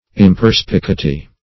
Search Result for " imperspicuity" : The Collaborative International Dictionary of English v.0.48: Imperspicuity \Im*per`spi*cu"i*ty\, n. Lack of perspicuity or clearness; vagueness; ambiguity.